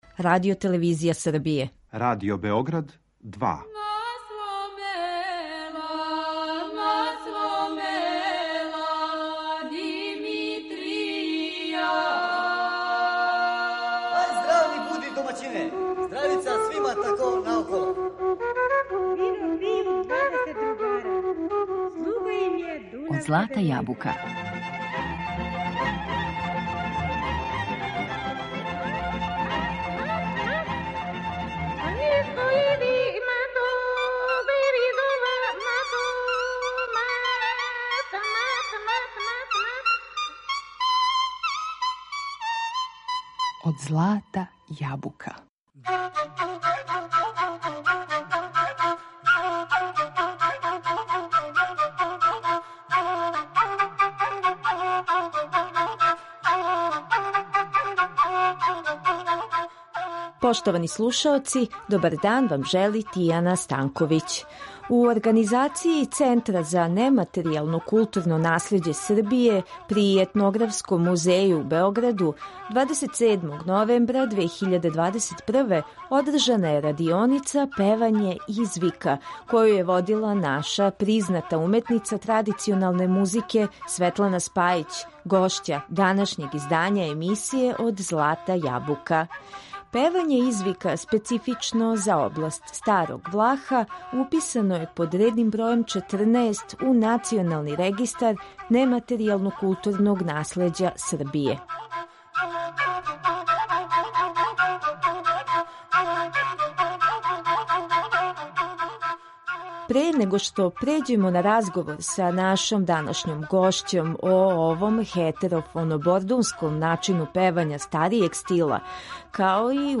Разговарали смо о овом специфичном вокалном облику, који је карактеристичан за област Старог Влаха, а уписан под редним бројем 14 у Национални регистар нематеријалног културног наслеђа Србије. Осим теренских снимака песама „из вика" у извођењу наших најбољих традиционалних певача